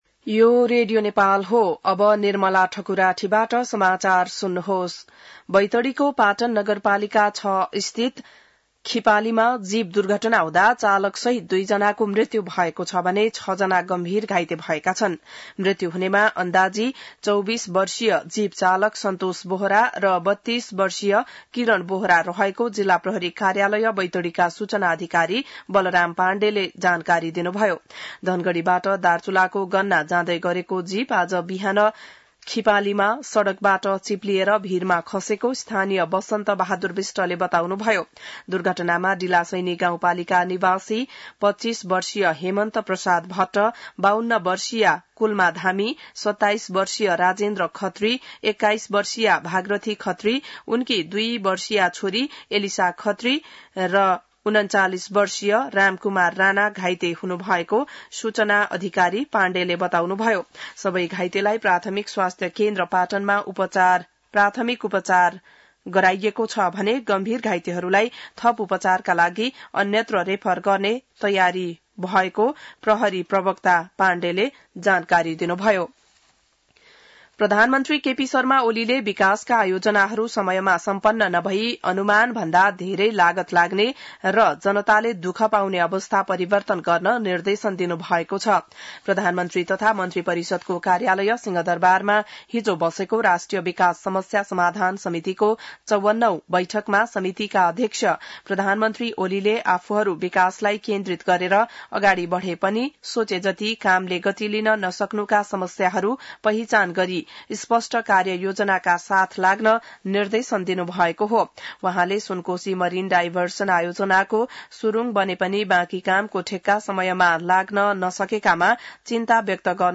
बिहान १० बजेको नेपाली समाचार : २८ असार , २०८२